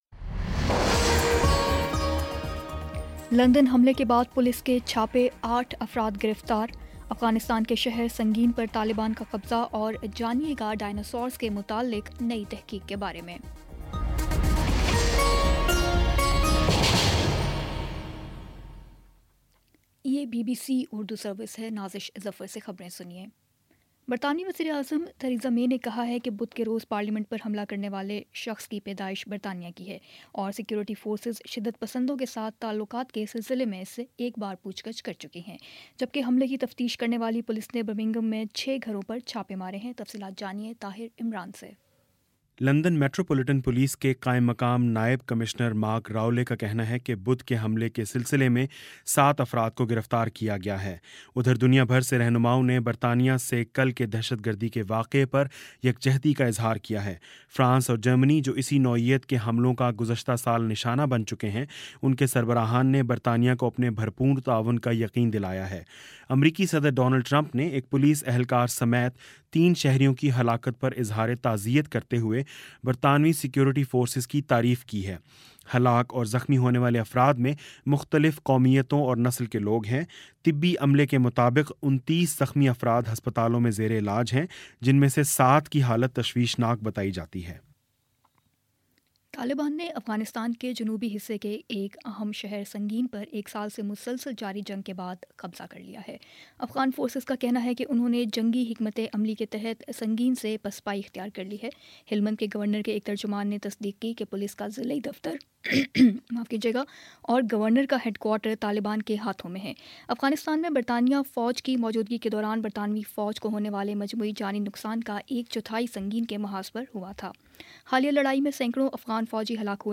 مارچ 23 : شام چھ بجے کا نیوز بُلیٹن